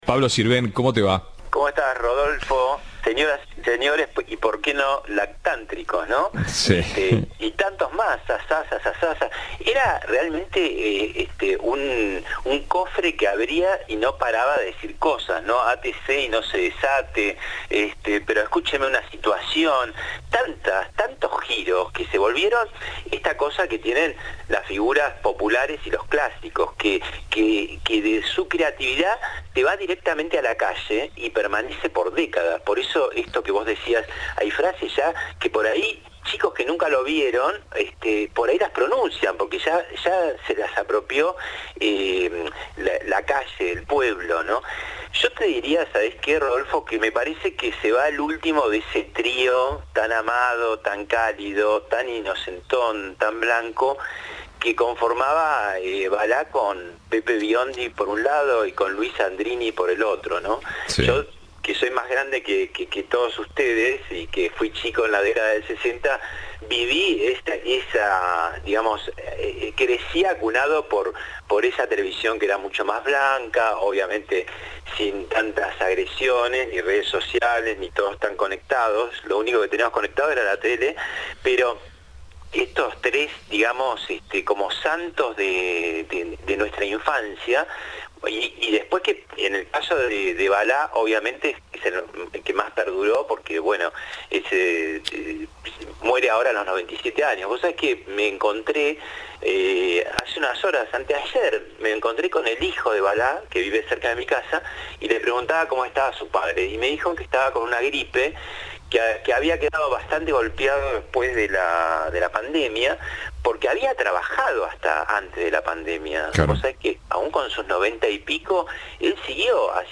"El pueblo se apropió de sus frases", dijo el periodista, al referirse a la reciente muerte del célebre humorista.